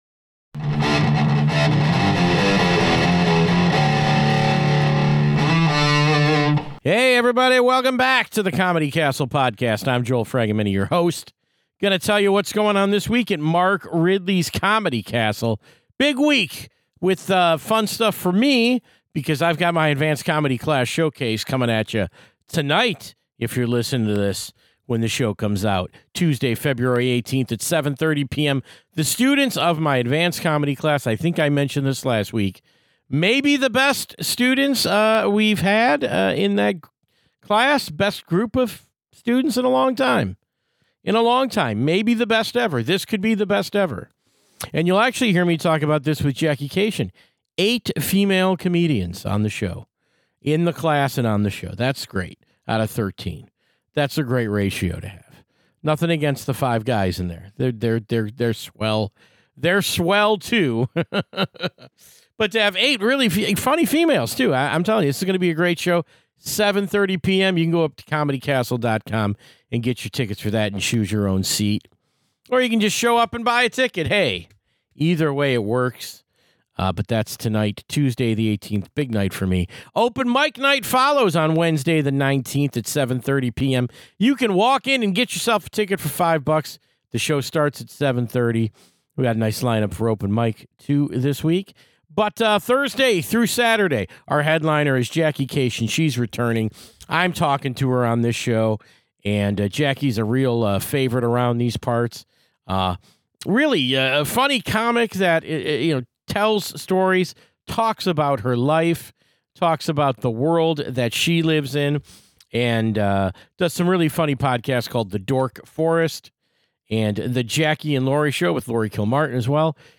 Jackie is a first rate comedian and podcaster who returns to this show to talk about making a new comedy special in a social media world, media recs for dorks, and the insanity of keeping up friendships on the road.